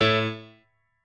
admin-leaf-alice-in-misanthrope/piano34_1_018.ogg at a8990f1ad740036f9d250f3aceaad8c816b20b54